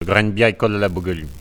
Elle provient de Saint-Gervais.
Catégorie Locution ( parler, expression, langue,... )